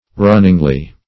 runningly - definition of runningly - synonyms, pronunciation, spelling from Free Dictionary Search Result for " runningly" : The Collaborative International Dictionary of English v.0.48: Runningly \Run"ning*ly\, adv. In a running manner.